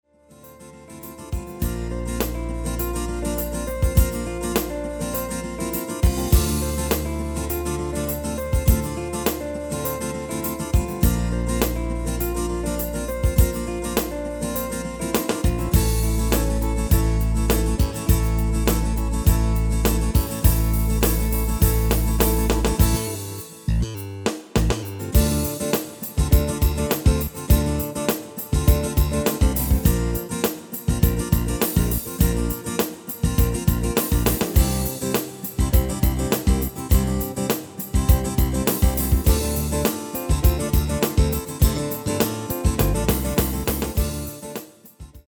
Demo/Koop midifile
Genre: Pop & Rock Internationaal
Toonsoort: D
- Géén vocal harmony tracks
Demo's zijn eigen opnames van onze digitale arrangementen.